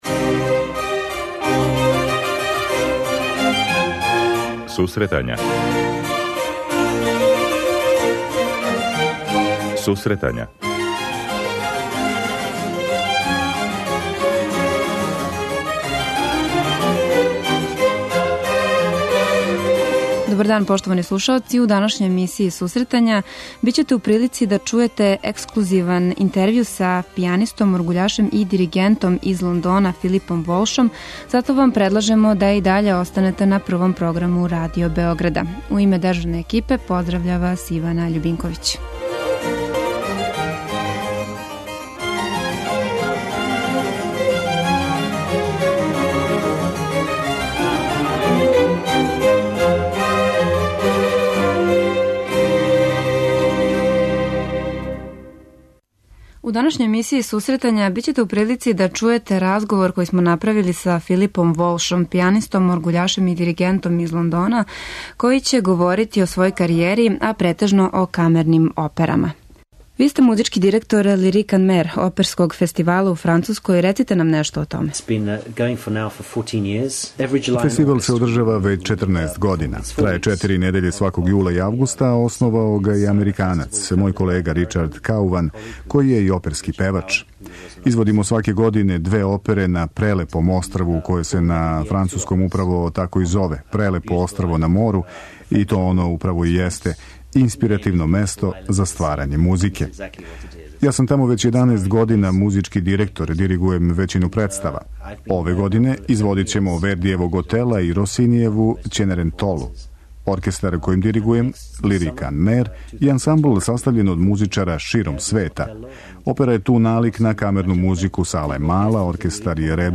У данашњој емисији моћи ћете да чујете интервју са познатим енглеским диригентом